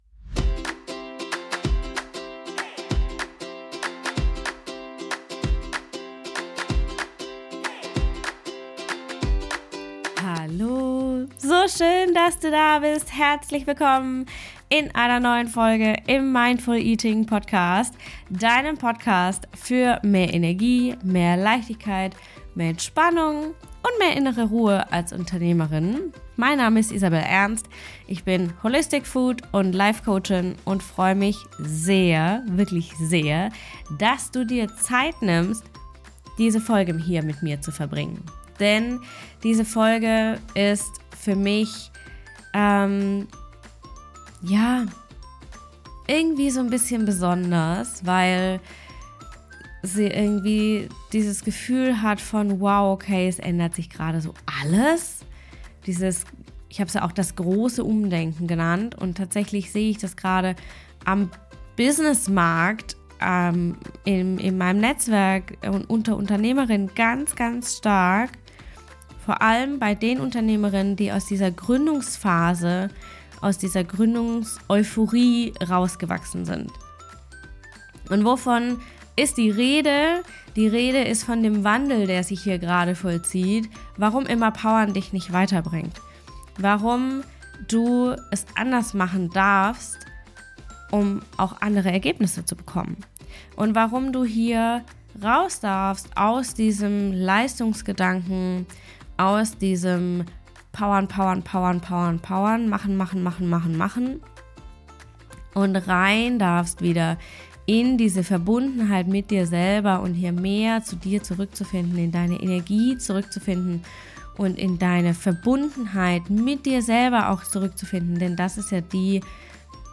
Das klären wir gemeinsam in dieser neuen powervollen Solo-Folge.